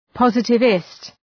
Προφορά
{‘pɒzıtı,vıst}